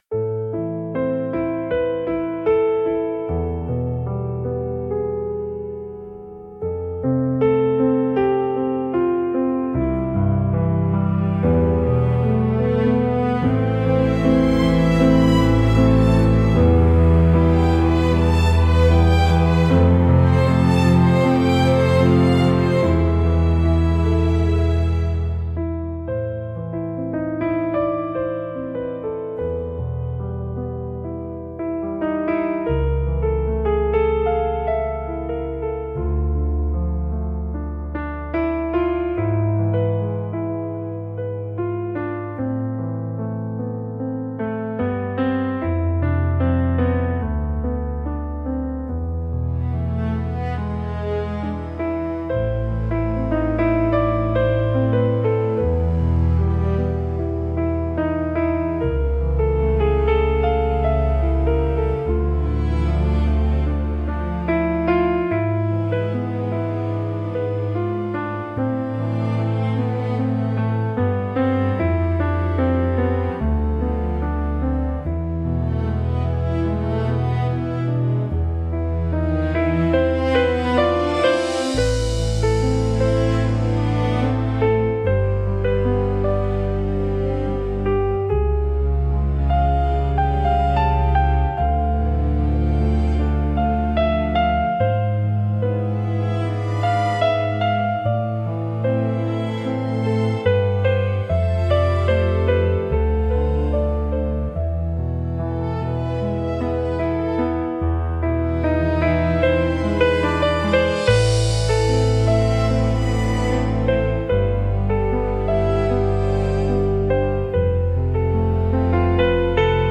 聴く人に共感や切実な感情を与え、場の雰囲気を切なく美しく彩るジャンルです。